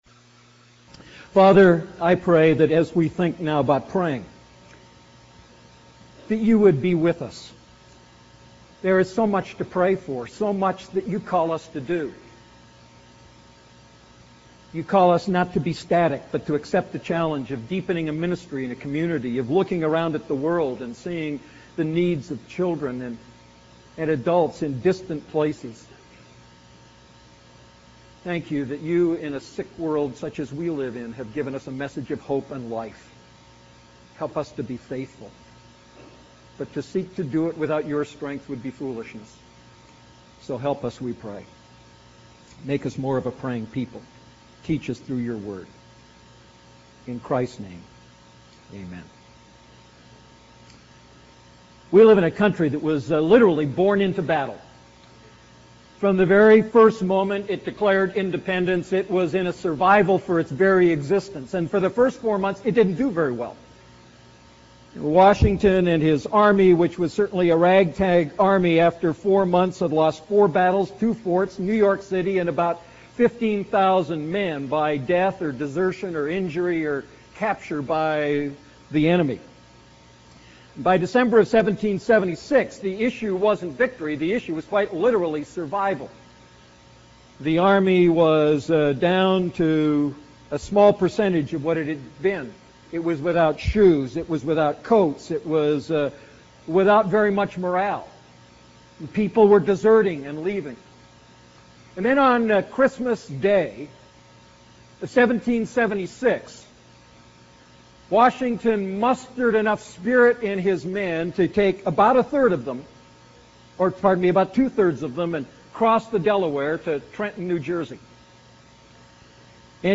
A message from the series "Prayer."